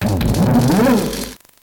Fichier:Cri 0604 NB.ogg